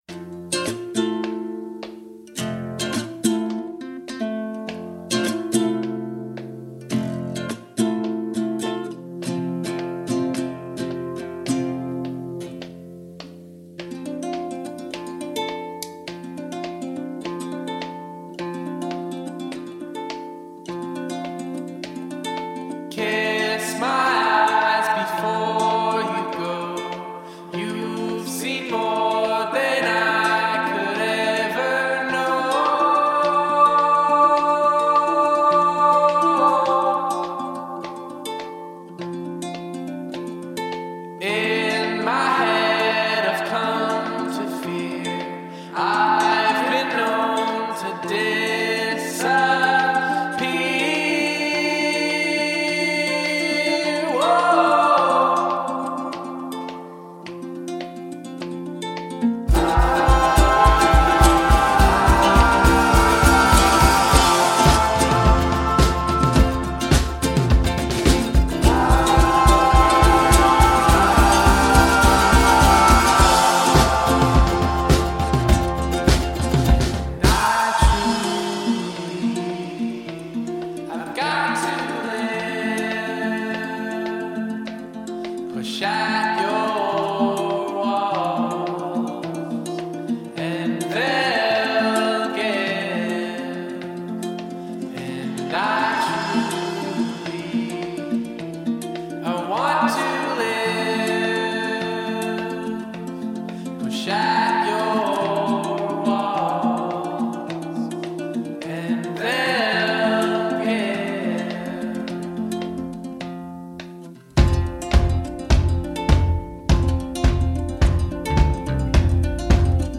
a slightly haunting yet inspiring effort